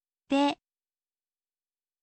ออกเสียง: de, เดะ